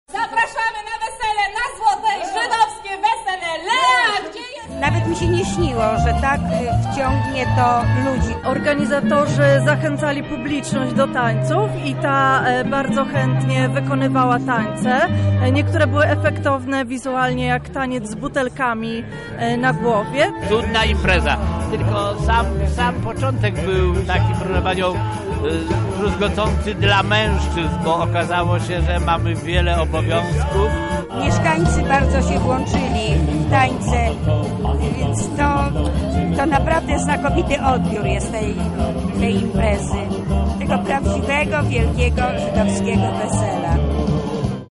Inscenizacja zaślubin w wykonaniu aktorów z Teatru Żydowskiego z Warszawy rozpoczęła się korowodem z Bramy Grodzkiej, który w takt żydowskich melodii zaprowadził biesiadników w miejsce hucznego przyjęcia.